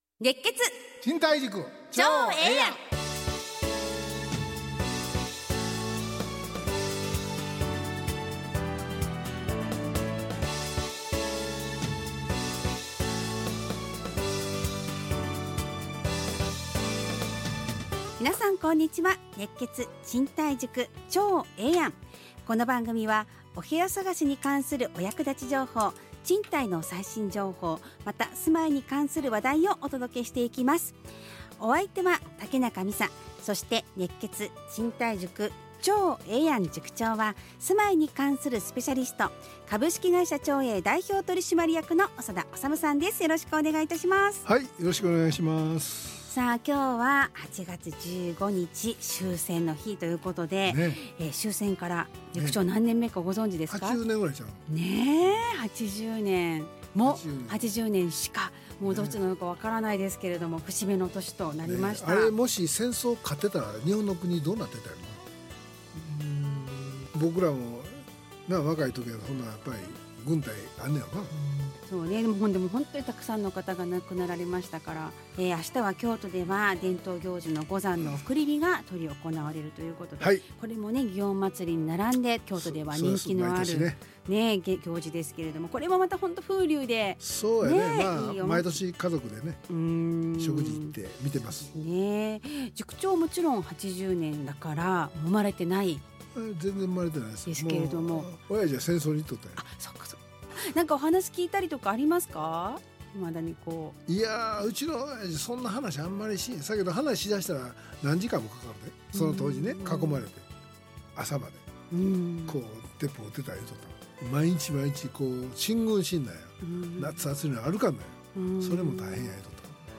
ラジオ放送 2025-08-18 熱血！